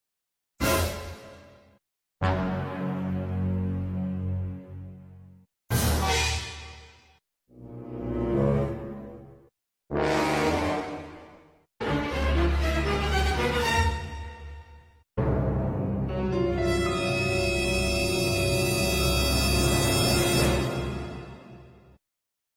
Funny Sound Effect Compilation For Sound Effects Free Download
Funny sound effect compilation for